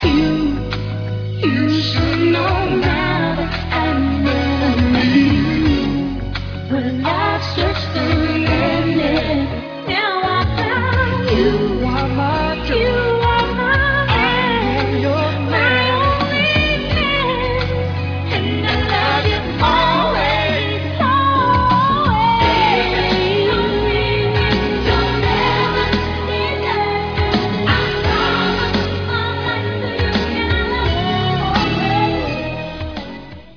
guitar and keyboards